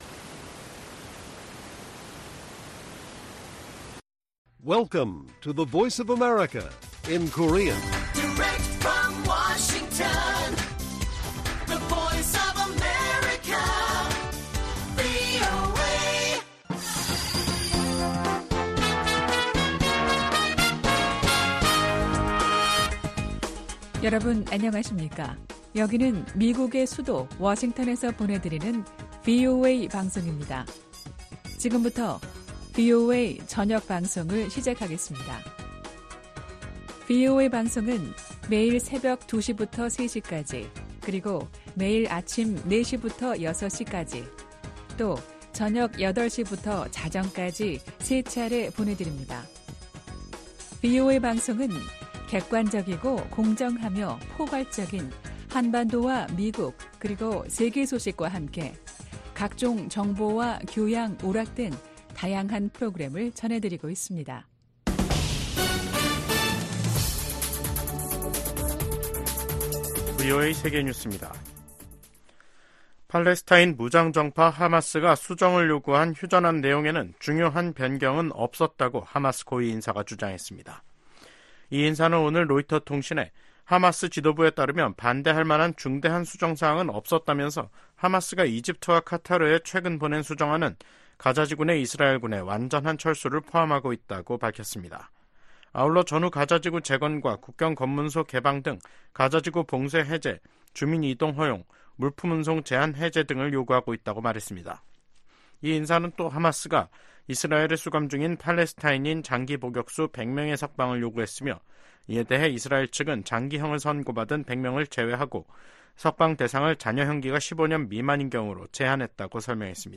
VOA 한국어 간판 뉴스 프로그램 '뉴스 투데이', 2024년 6월 13일 1부 방송입니다. 유엔 안보리에서 열린 북한 인권공개 회의에서 미국과 한국, 일본 등은 북한 인권 유린이 불법적인 무기 개발과 밀접한 관계에 있다고 지적했습니다. 미한일 등 50여개국과 유럽연합(EU)이 뉴욕 유엔본부에서 개선될 조짐이 없는 북한의 인권 상황에 대해 우려를 표명했습니다.